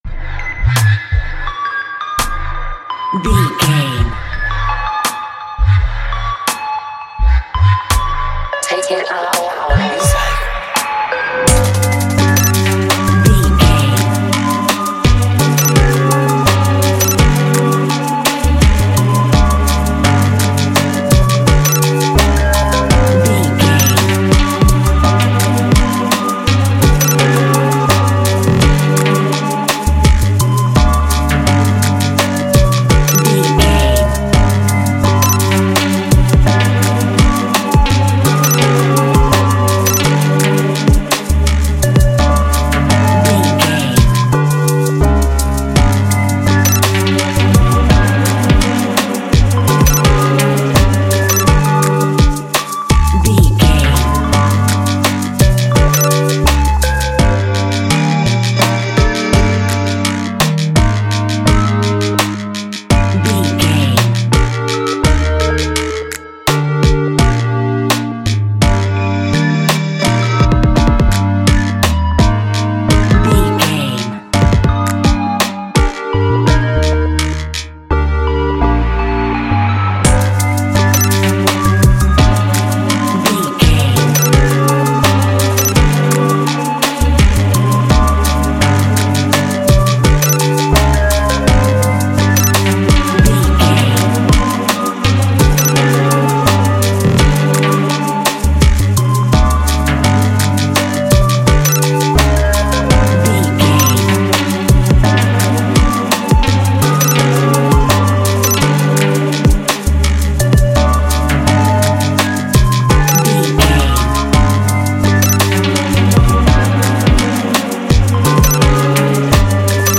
We are rather in an uncertain and nocturnal universe.
Ionian/Major
Slow
dissonant
menacing
mysterious